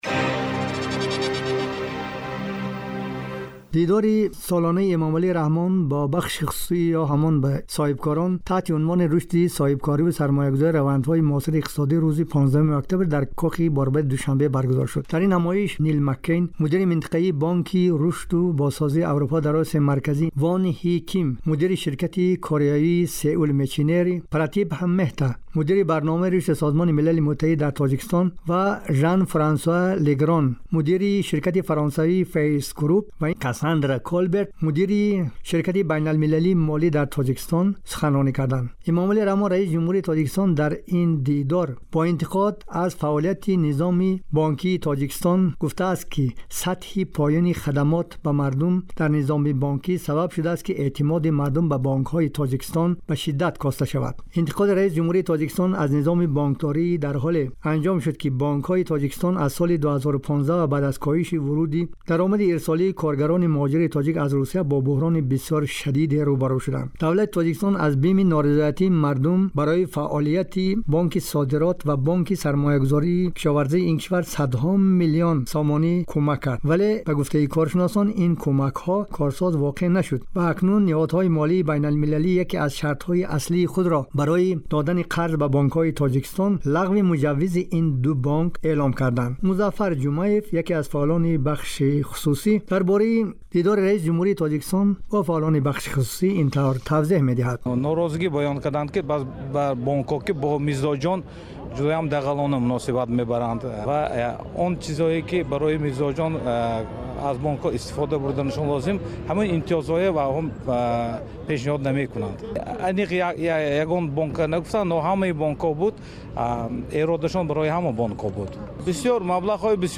گزارش ویژه : ناتوانی بانکهای تاجیکستان در بازپرداخت سپرده مشتریان